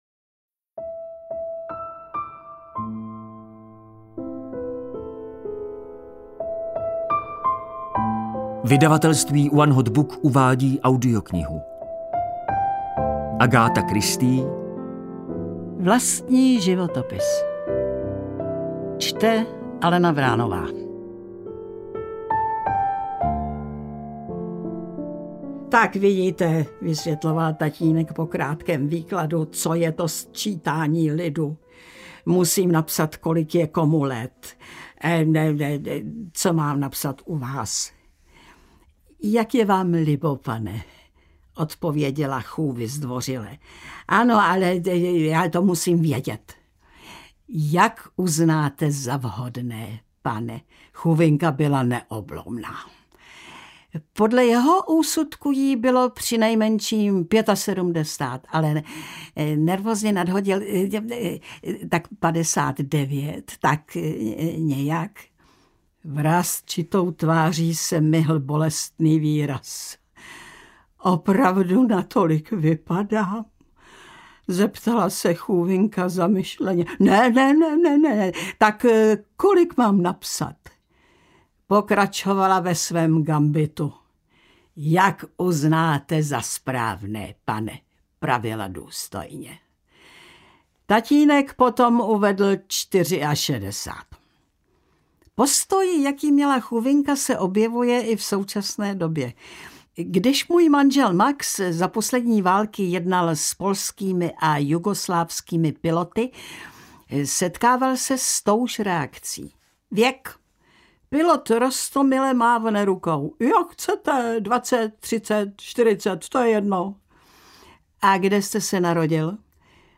Vlastní životopis audiokniha
Ukázka z knihy
• InterpretAlena Vránová